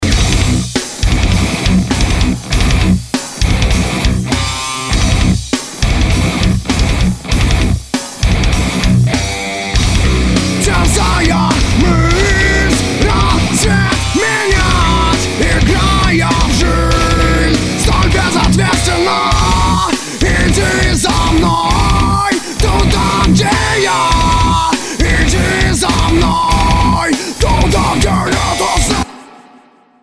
���� ����� - TSE X30/Ozone 4 - Alternative Metal